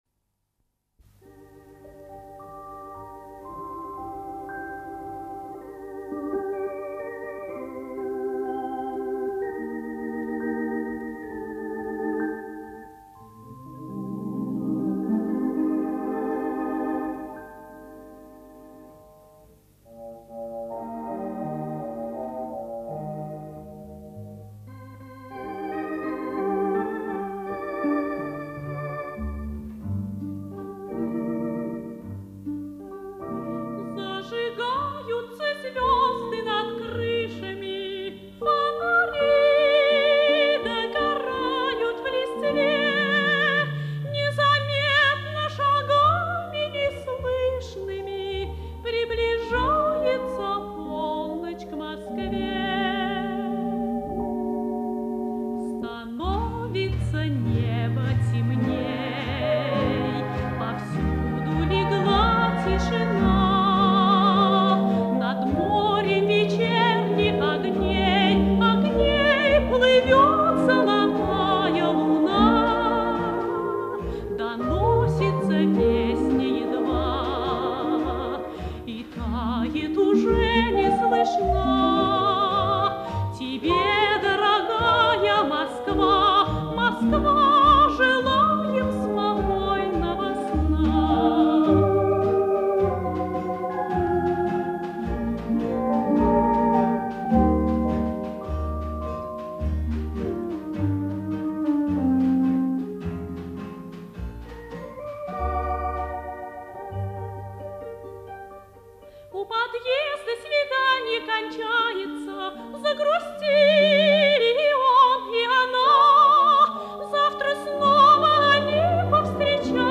Данная запись, судя по году, одна из первых студийных.